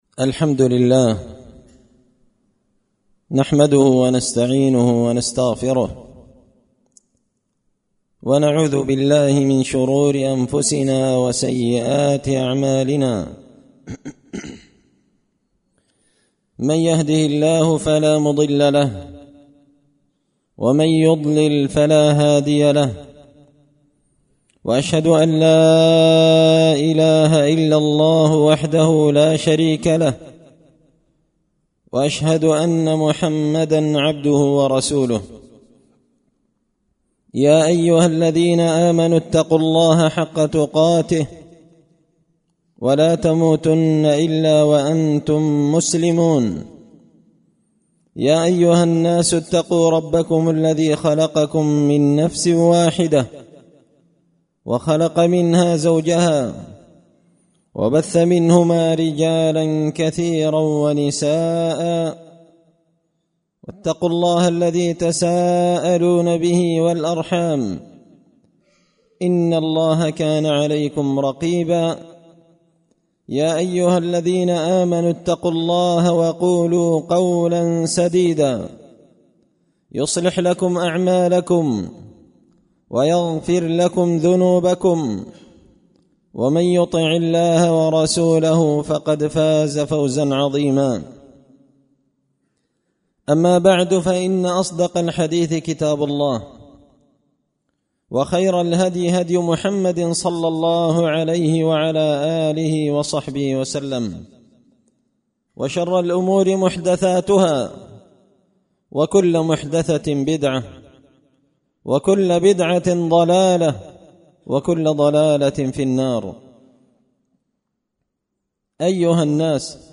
خطبة جمعة بعنوان – والذين يؤذون المؤمنين والمؤمنات بغير ما اكتسبوا فقد احتملوا بهتانا واثما
دار الحديث بمسجد الفرقان ـ قشن ـ المهرة ـ اليمن